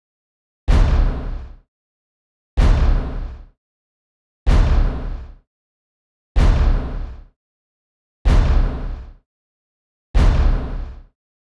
The below samples have effects artificially exaggerated for easier listening.
Bass drum samples first:
Samples in a loop, with no effect first:
LP_sample_nofilter_v1.m4a